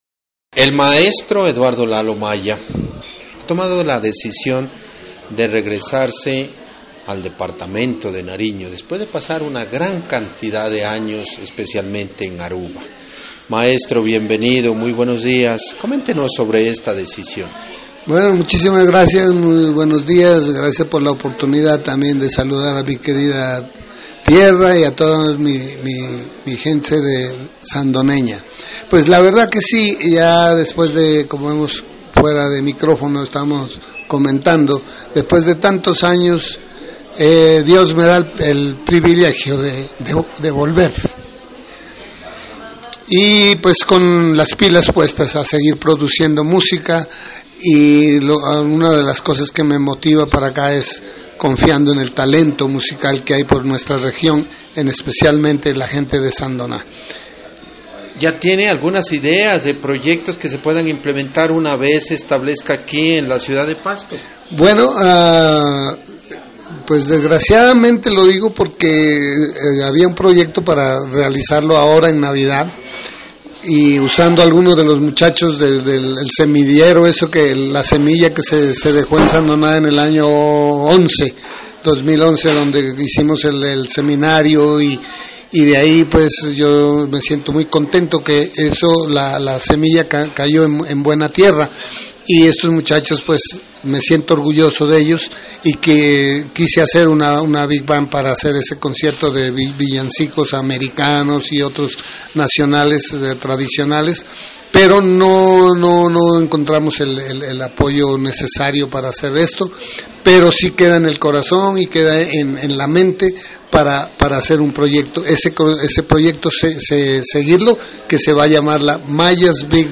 Esta es la entrevista que grabamos el viernes 11 de diciembre en la ciudad de Pasto.